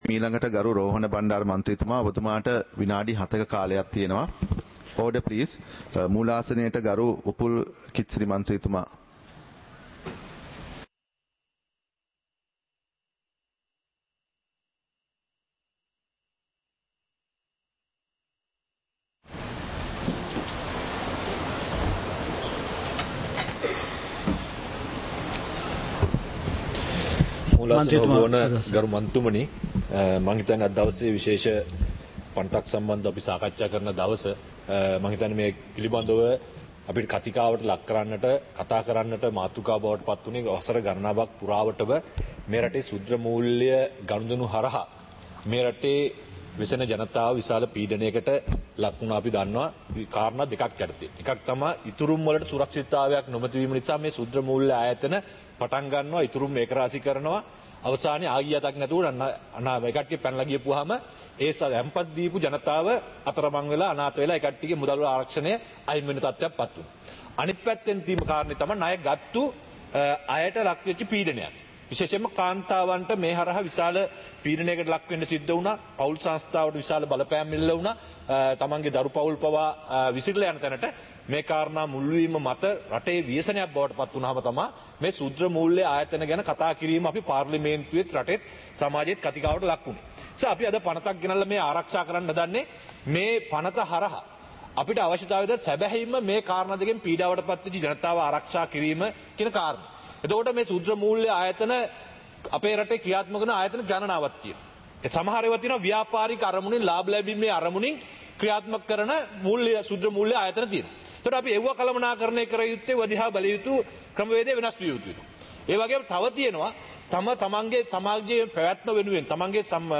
Proceedings of the House (2026-03-04)
Parliament Live - Recorded